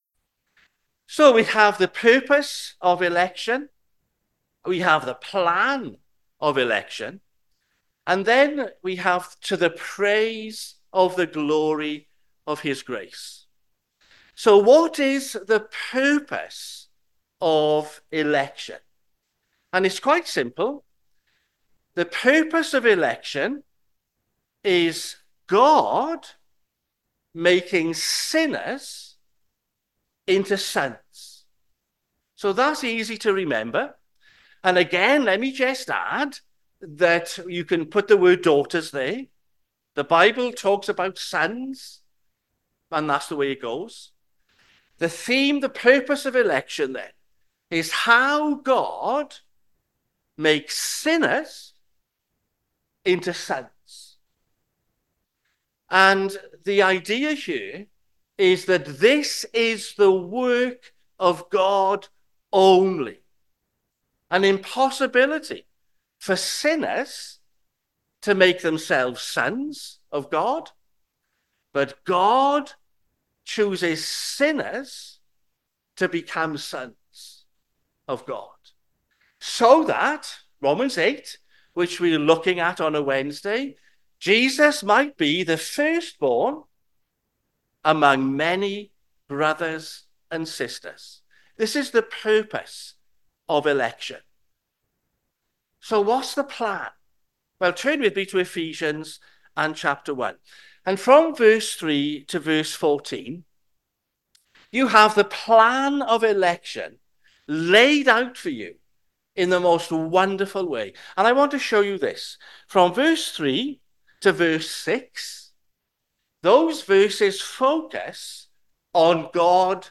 Sermons Tabernacle Church - 19th January 2025 am - Blessed are the people He has chosen Play Episode Pause Episode Mute/Unmute Episode Rewind 10 Seconds 1x Fast Forward 30 seconds 00:00 / 23:50 Subscribe Share RSS Feed Share Link Embed